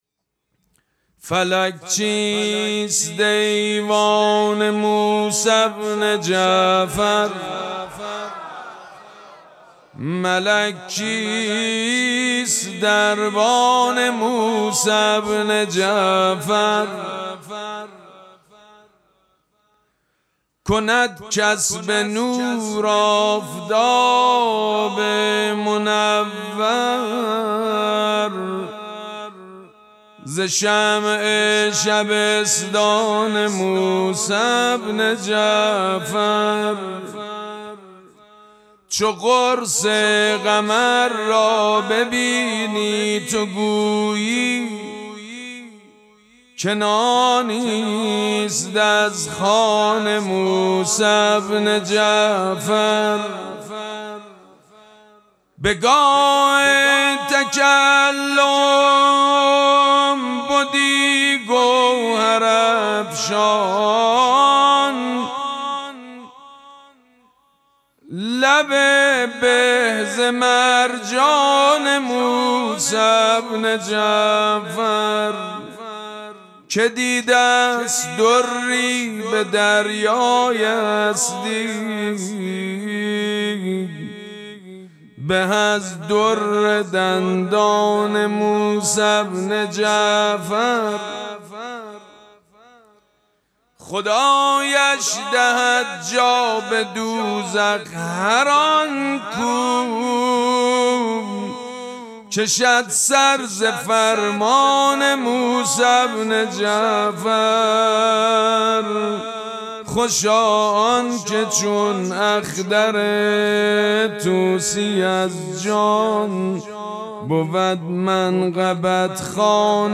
مراسم مناجات شب پنجم ماه مبارک رمضان چهارشنبه‌ ۱۵ اسفند ماه ۱۴۰۳ | ۴ رمضان ۱۴۴۶ ‌‌‌‌‌‌‌‌‌‌‌‌‌هیئت ریحانه الحسین سلام الله علیها
سبک اثــر مدح مداح حاج سید مجید بنی فاطمه